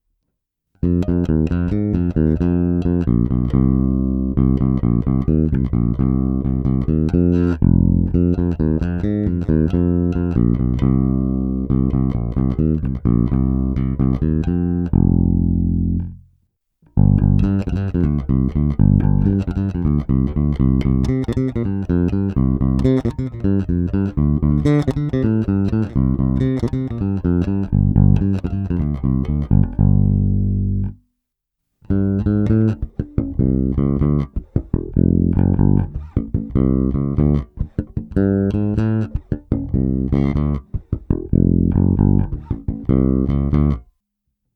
Nejvíc se mi líbí zvuk s nepatrným upřednostněním kobylkového snímače, prostě jen jsem lehce cuknul ze střední polohy směrem ke kobylce, a stejným způsobem jsem lehce cuknul s basy a výškami do plusu.